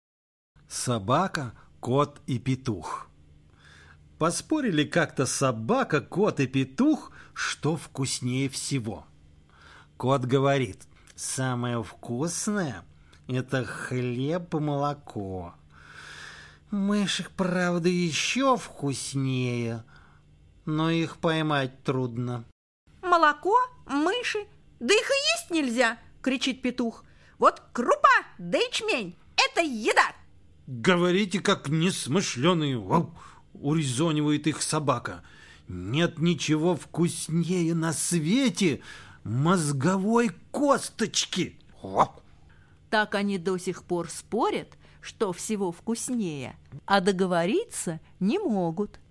Собака, кот и петух - латышская аудиосказка - слушать онлайн